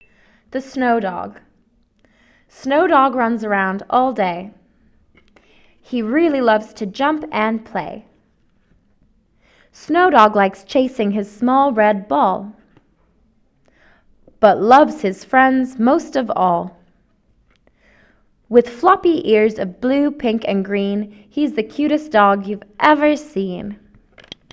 snowdog américain